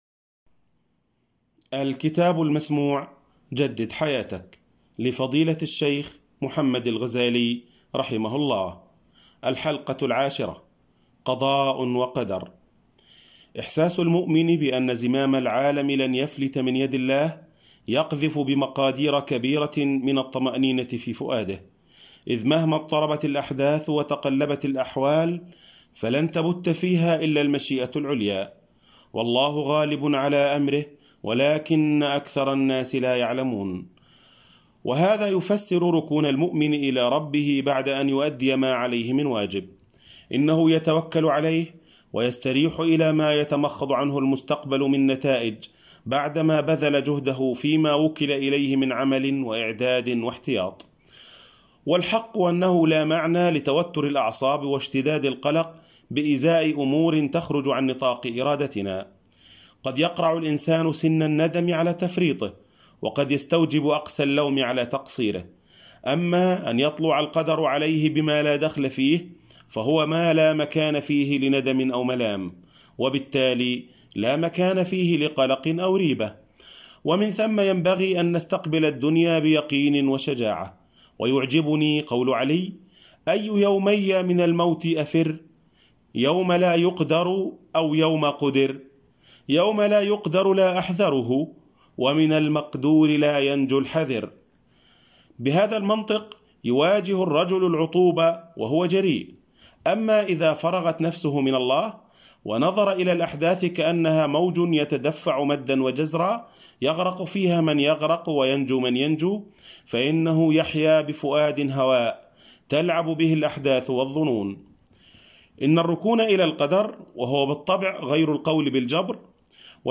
أرشيف الإسلام - الكتب مسموعة - كتب الفكر الإسلامي - جدد حياتك - محمد الغزالي